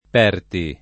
[ p $ rti ]